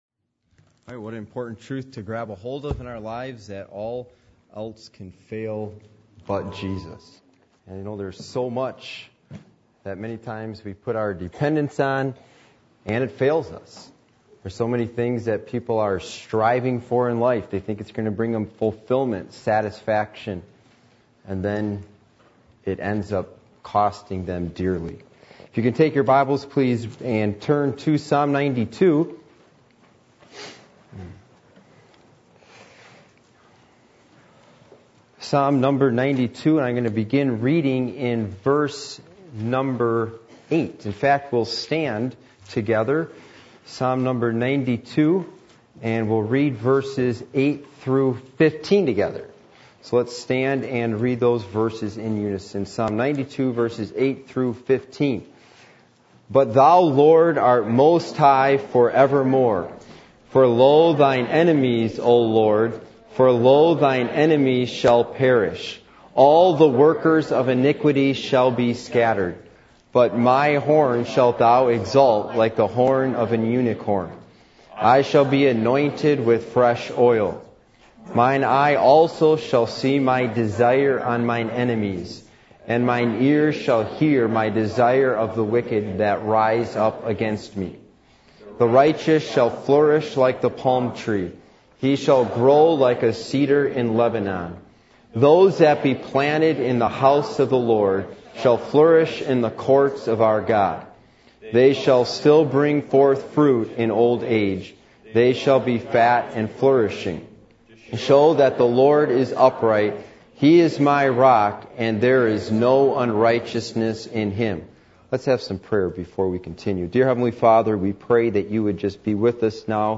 Passage: Psalm 92:8-8:15 Service Type: Sunday Evening